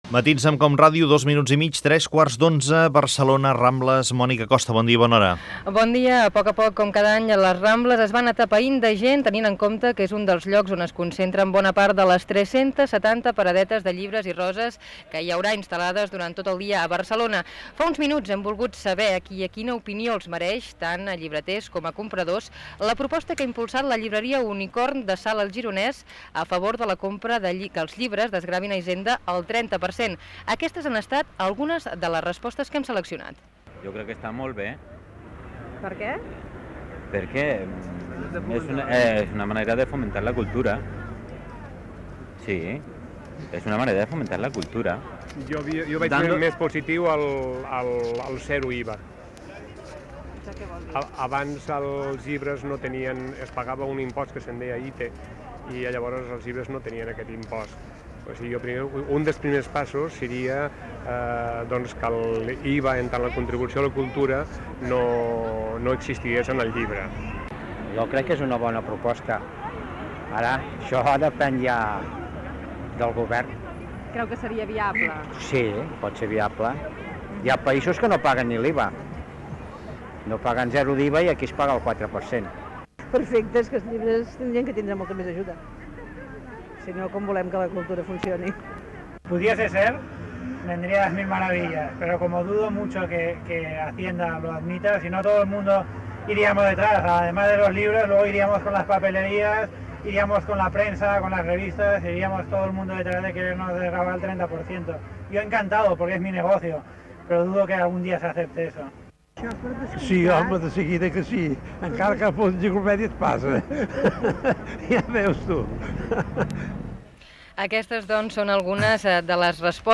Identificació del programa i connexió amb la unitat mòbil que està a la Rambla de Barcelona en la diada de Sant Jordi
Info-entreteniment